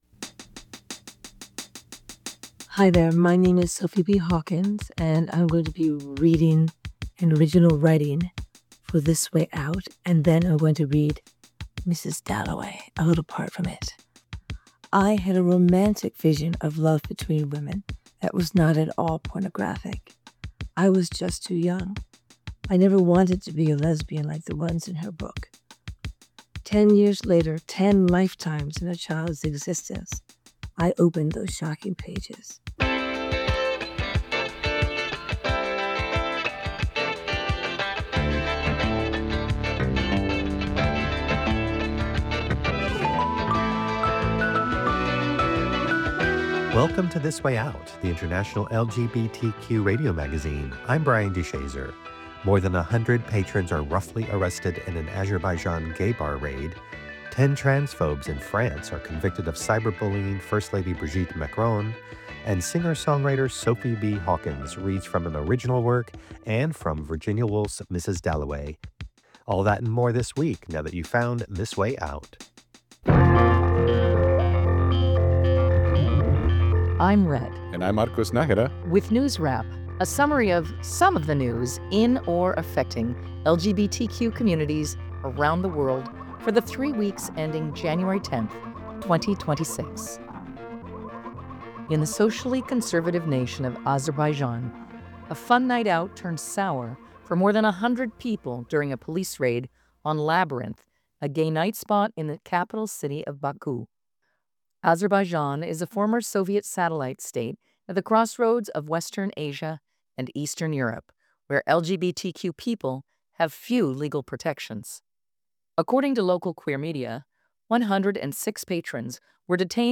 Sophie B. Hawkins reads from Woolf & Hawkins + global LGBTQ news
The International LGBTQ radio magazine